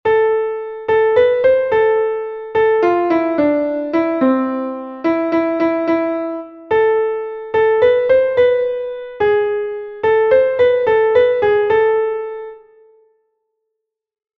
Entoación a capella
Melodía 6/8 en La m